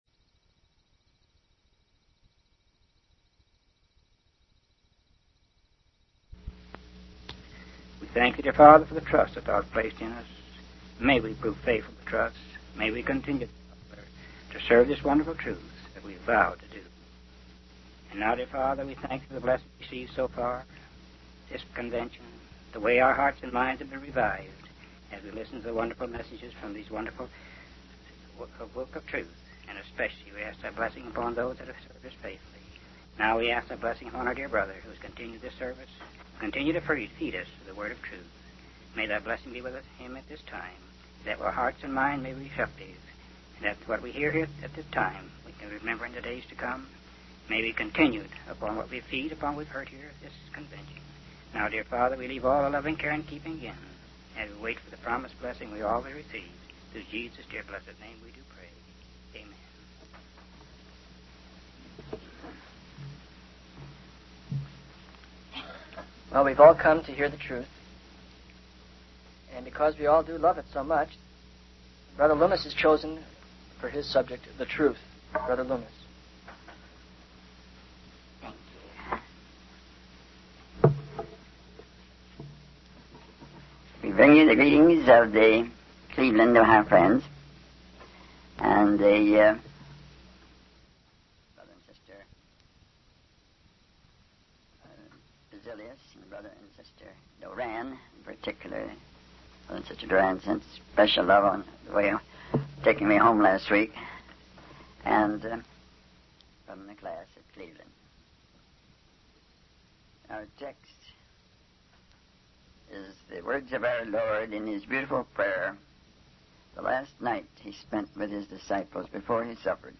From Type: "Discourse"
Given at Phoenix, AZ November 1972